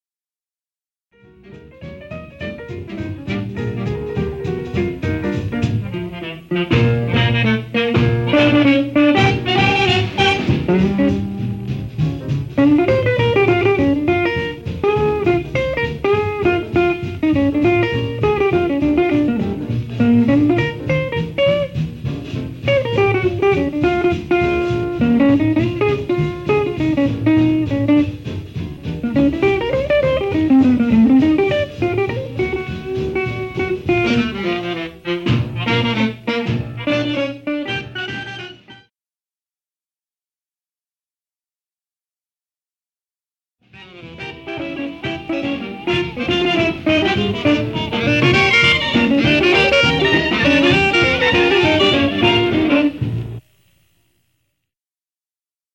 Guitar
LISTEN   Guitar Solo / Coda